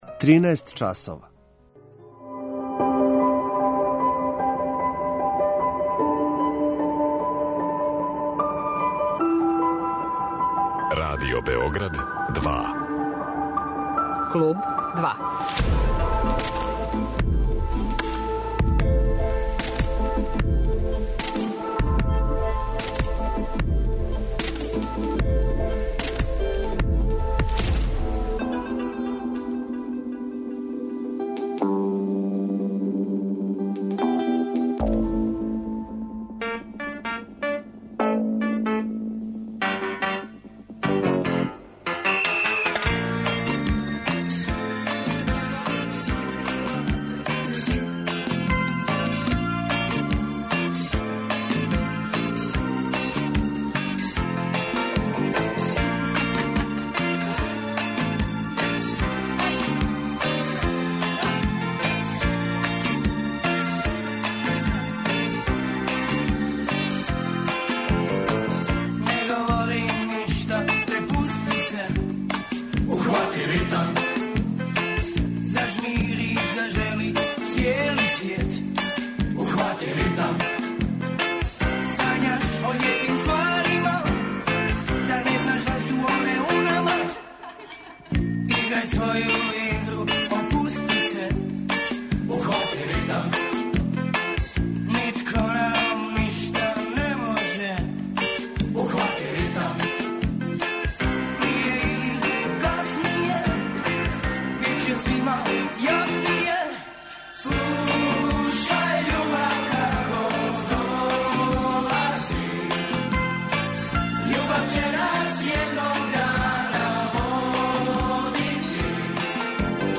У занимљивом и динамичном разговору који смо недавно забележили наш саговорник говори о песмама, почецима и трајању овог култног поп-рок састава.
Аки Рахимовски, певач групе Парни ваљак